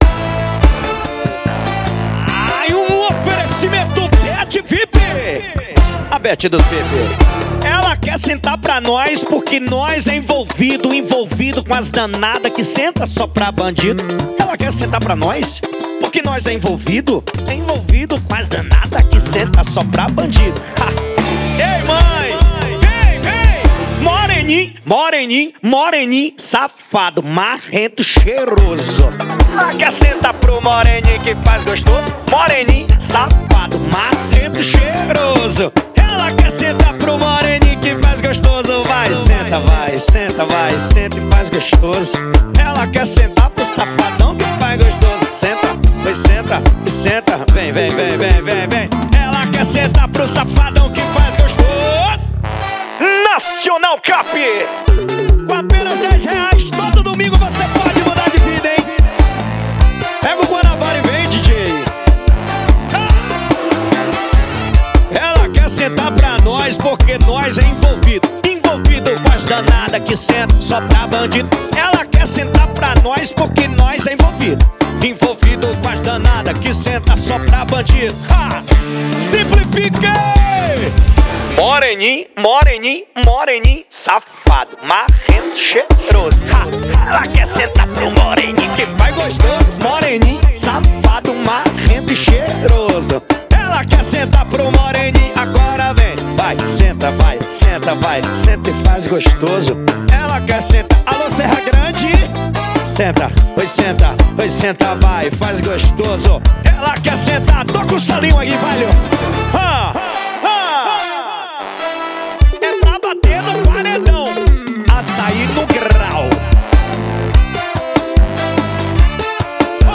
2024-02-14 22:38:23 Gênero: Forró Views